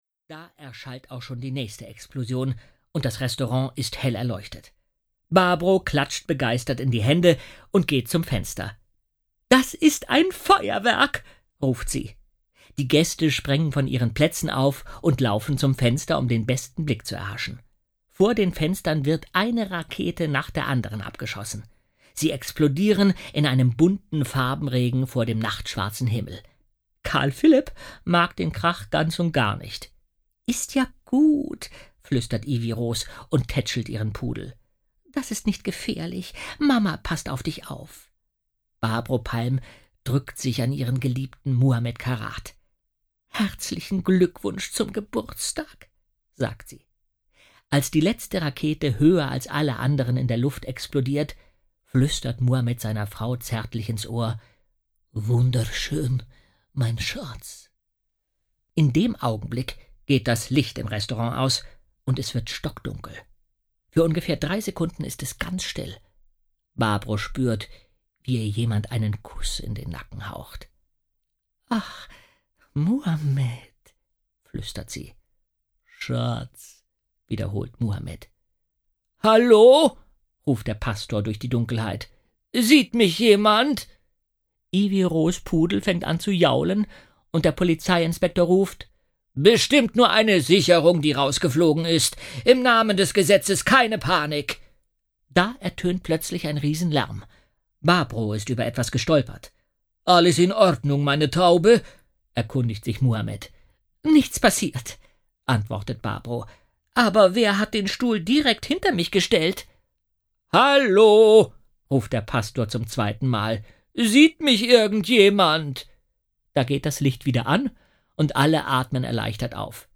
Sprecher: Jens Wawrczeck. 1 CD.
Jens Wawrczeck (Sprecher)